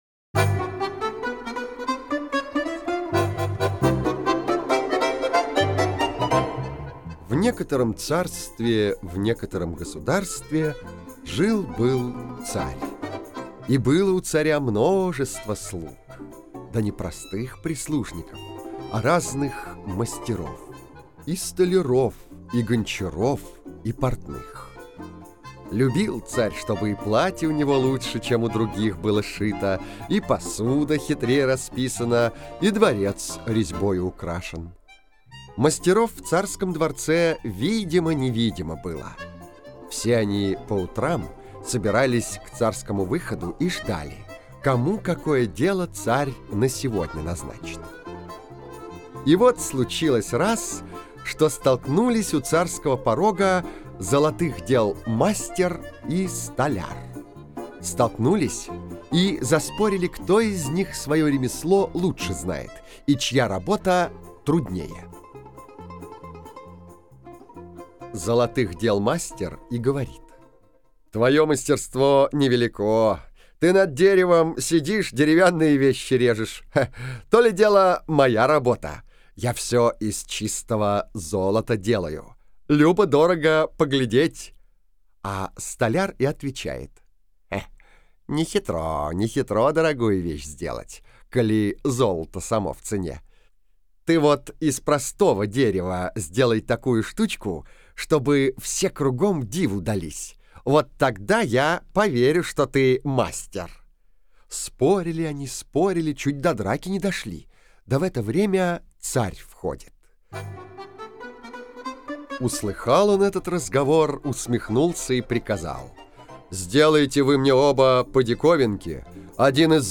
Аудио сказка «Деревянный орел».